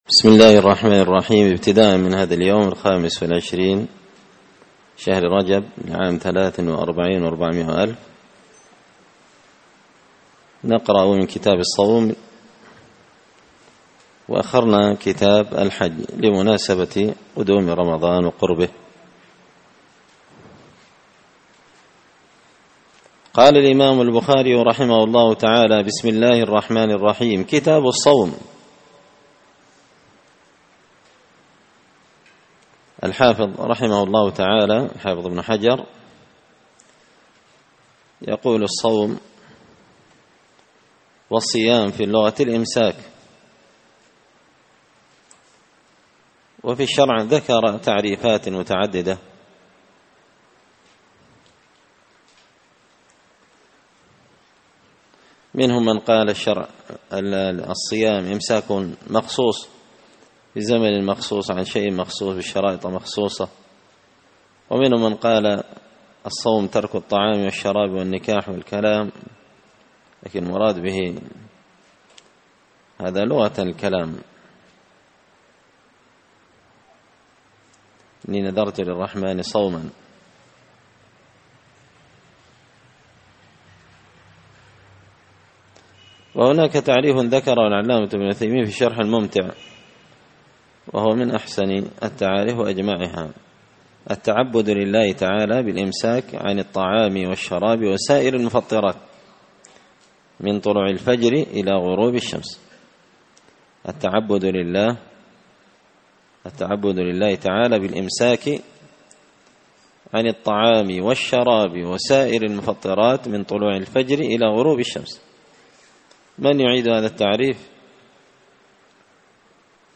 الأثنين 28 شعبان 1444 هــــ | 2- كتاب الصيام، الدروس، شرح صحيح البخاري | شارك بتعليقك | 10 المشاهدات